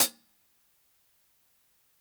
635_HH_SOFT .wav